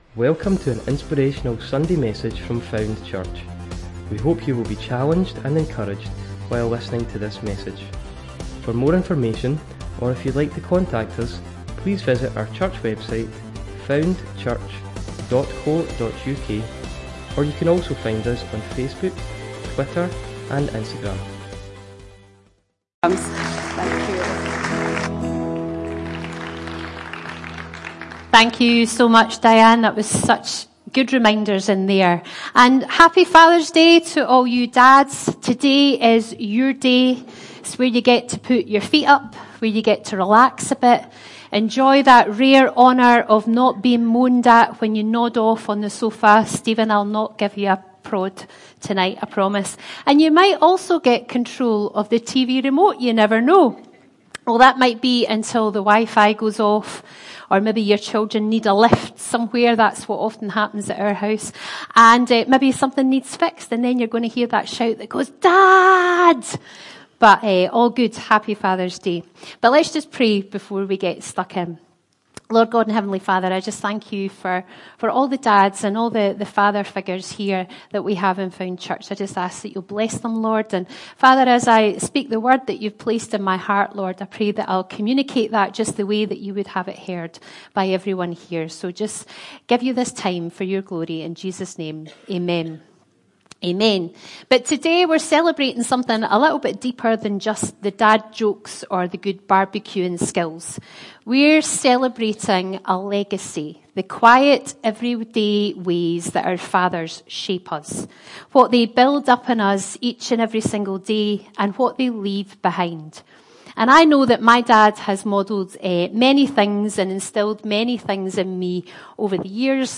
Father's Day service